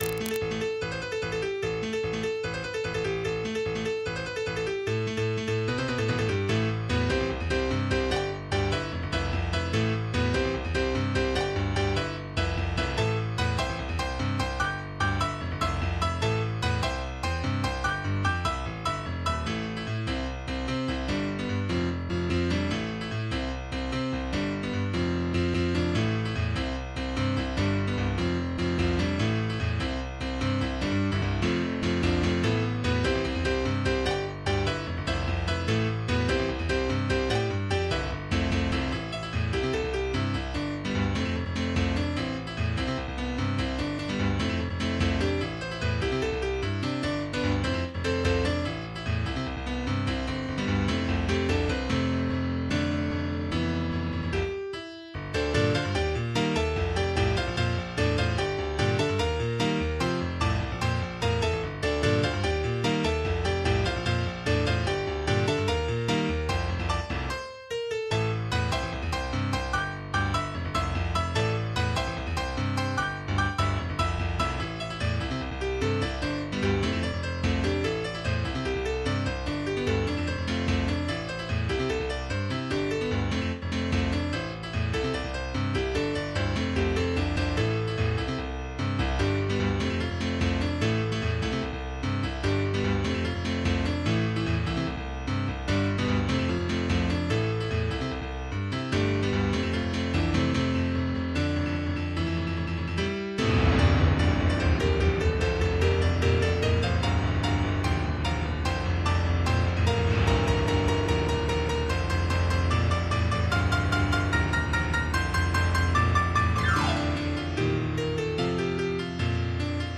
MIDI 18.64 KB MP3 (Converted) 2.43 MB MIDI-XML Sheet Music